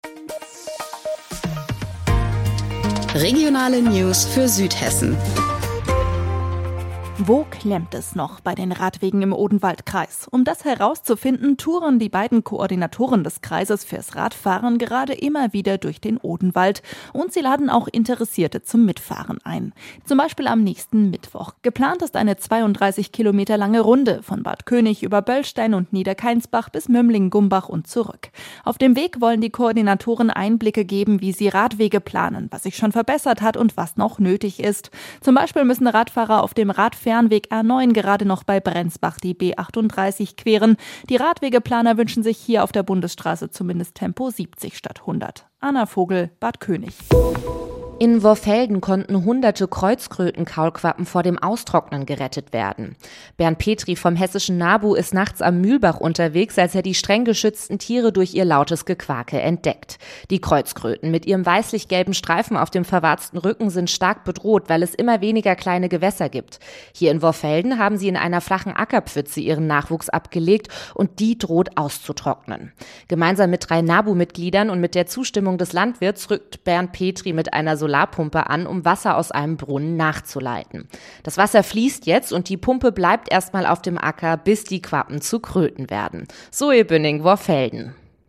Mittags eine aktuelle Reportage des Studios Darmstadt für die Region